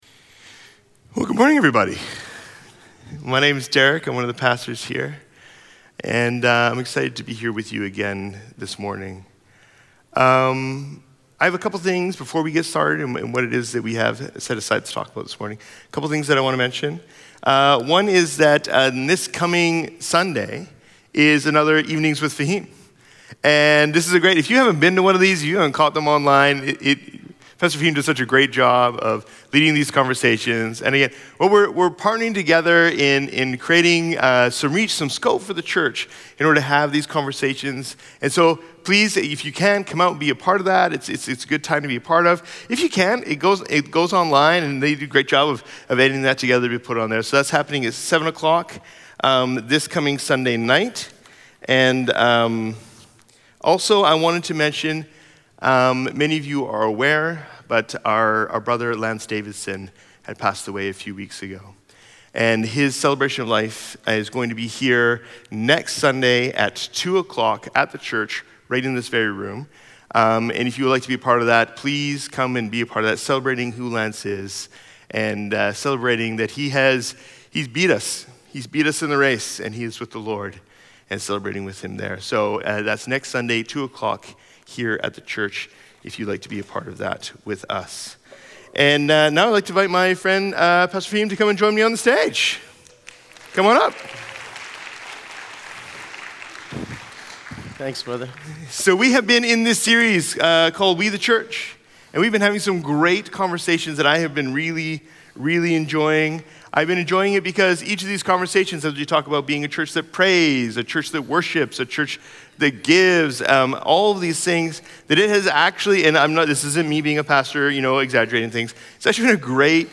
Sermons | Riverside Community Church